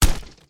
Headshot01.wav